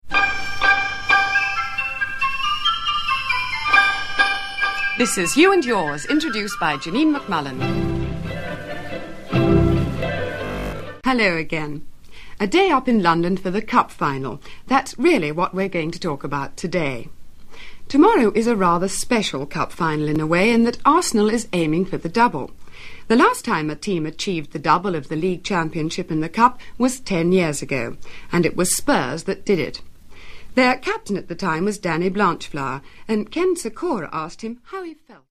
The beautifully titled 'Can I Help You', 'Listening Post', 'You & Your Money' and 'In Practice' were subsumed into this new weekday magazine programme.